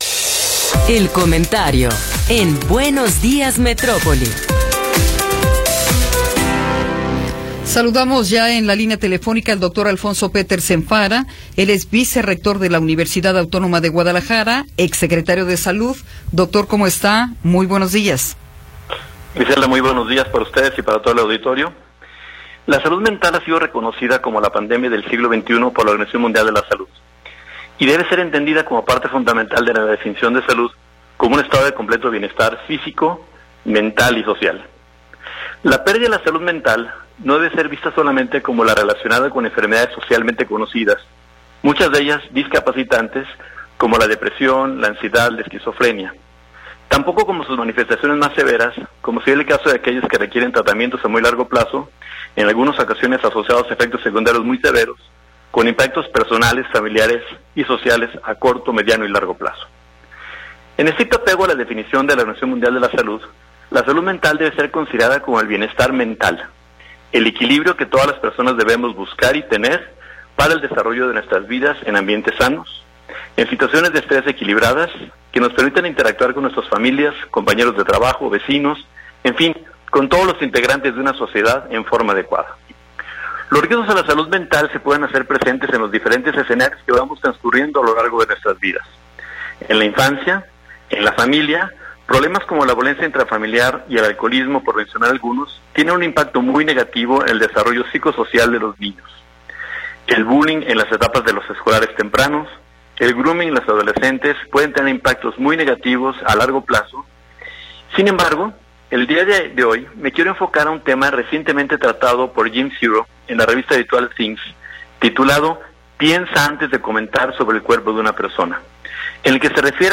Comentario de Alfonso Petersen Farah – 5 de Octubre de 2022
El Dr. Alfonso Petersen Farah, vicerrector de ciencias de la salud de la UAG y exsecretario de salud del estado de Jalisco, nos habla sobre la salud mental.